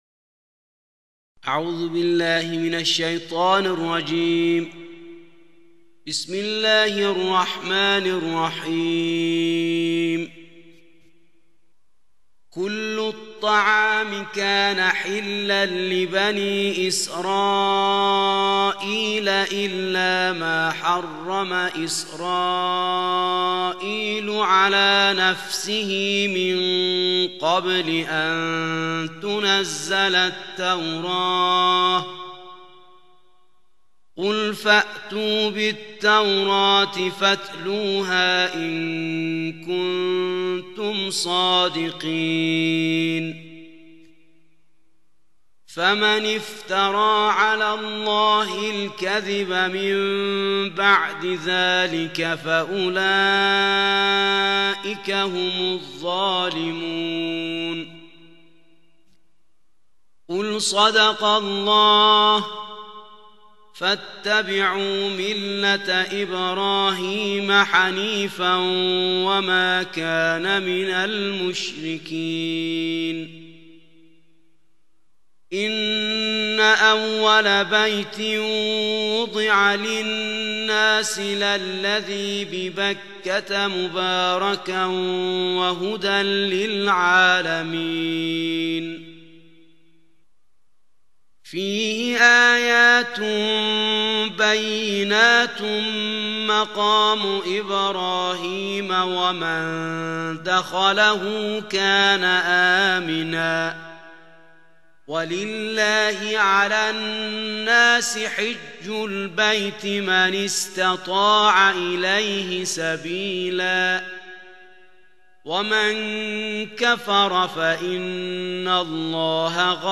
صوت تلاوت ترتیل جزء چهارم قرآن کریم